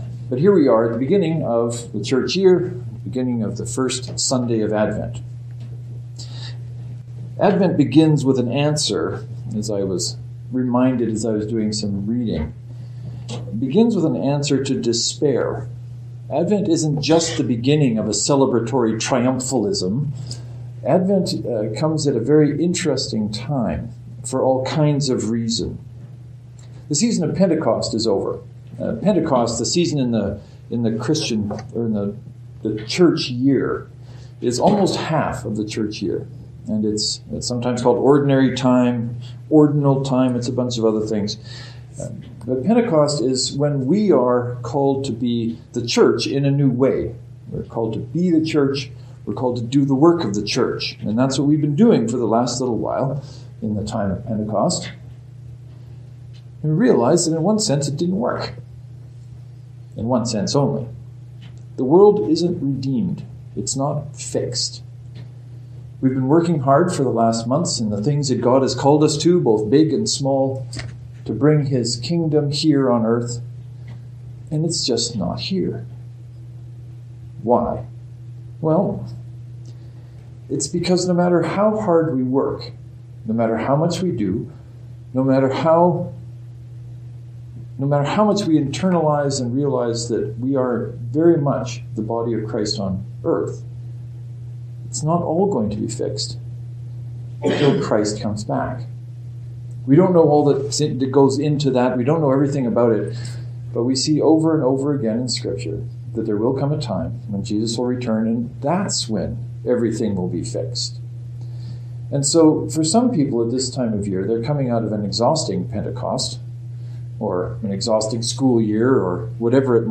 By the way, I was quite conscious of the similarity between the sermon title and the name of my blog . . . and it has made me happy ever since.